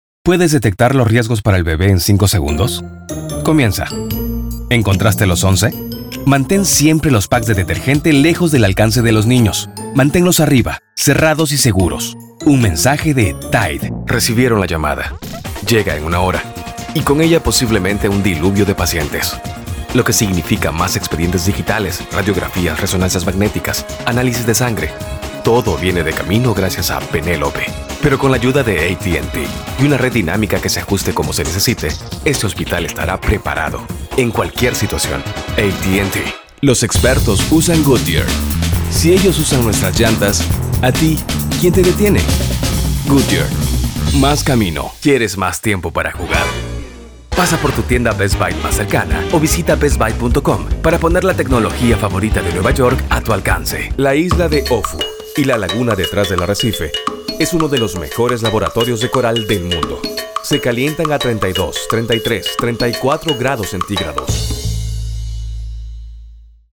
Commercial VO and Character.
Languages: Spanish (Latin American) Accent: Mexican, South American (Argentinian), South American (Peruvian) Ages: Middle Aged, Senior, Teen, Young Adult Special Skills: Animation, Audiobooks, Business, Documentaries, Educational, Internet Video, Movie Trailers, Podcasting, Radio, Telephone, Television, Videogames
Sprechprobe: Industrie (Muttersprache):
MADURO 3.mp3